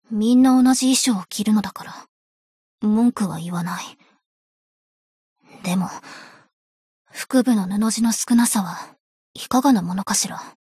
贡献 ） 协议：Copyright，其他分类： 分类:爱慕织姬语音 您不可以覆盖此文件。